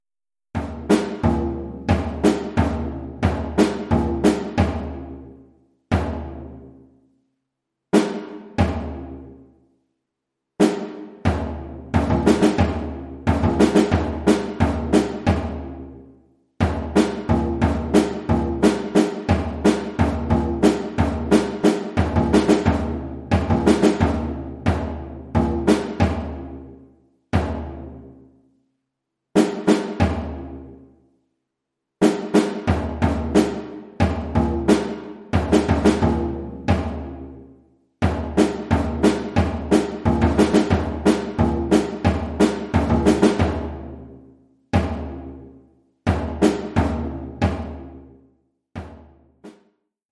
Oeuvre pour tambour seul.
Niveau : débutant, 1ère année.